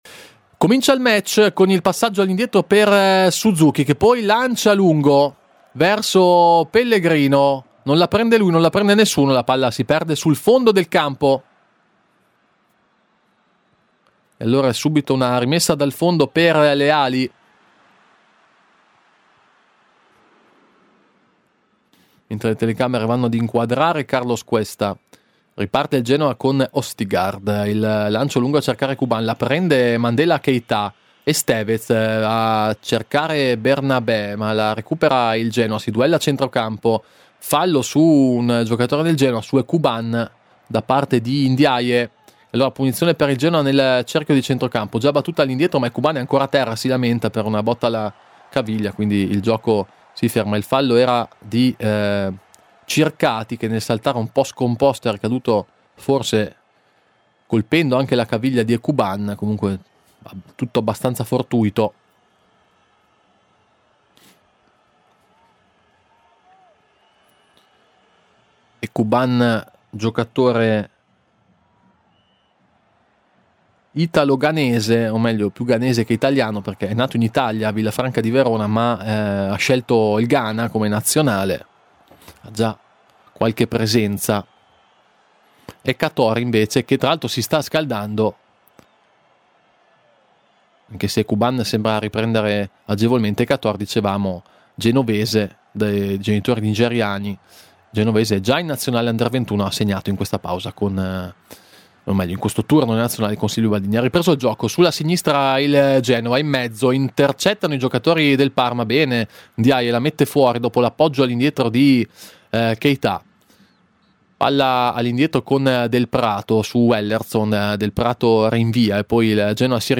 Genoa-Parma: radiocronaca